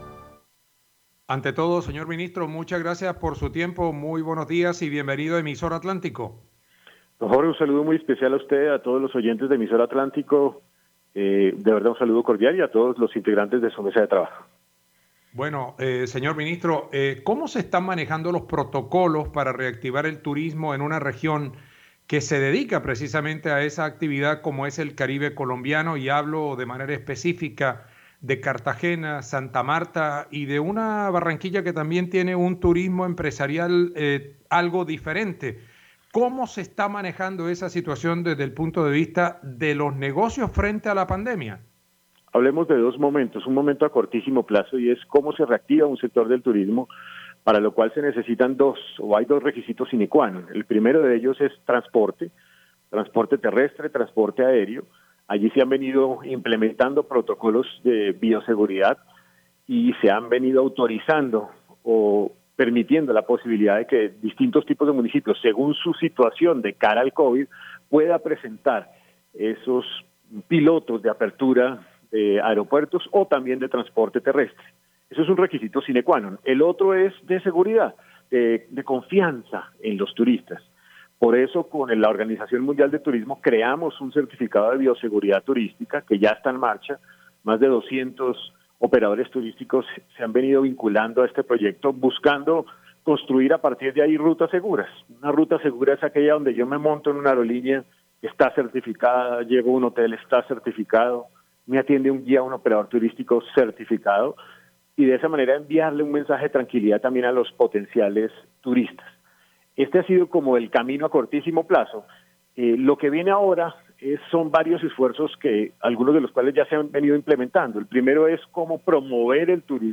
El ministro de Comercio, Industria y Turismo, José Manuel Restrepo, en dialogo con Emisora Atlántico, advirtió que el comportamiento del Coronavirus en Colombia es vital para que se vaya reactivando por completo el turismo y otros sectores de la economía.